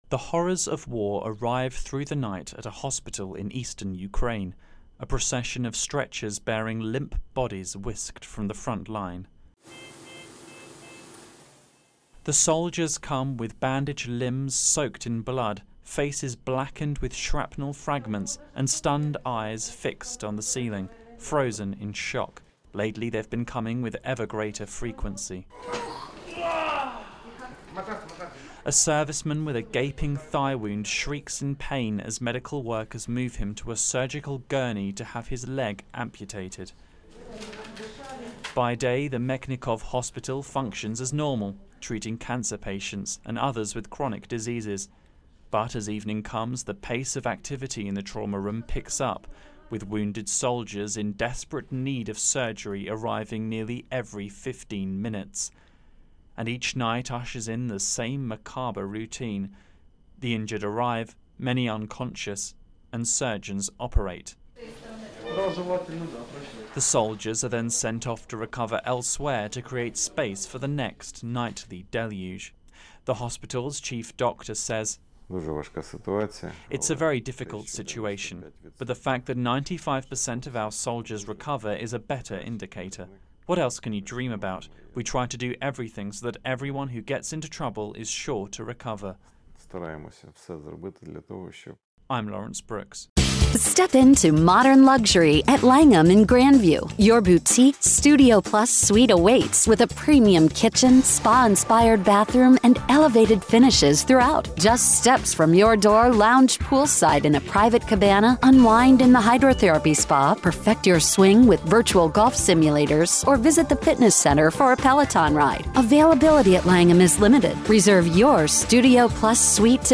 reports on Russia Ukraine War Hospital - a Ukrainian hospital facing a nightly deluge of injured soldiers from the war's front lines.